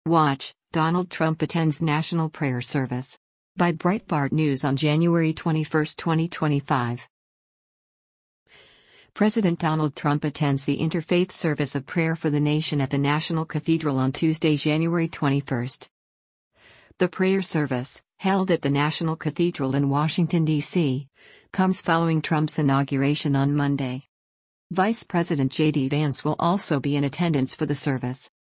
President Donald Trump attends the Interfaith Service of Prayer for the Nation at the National Cathedral on Tuesday, January 21.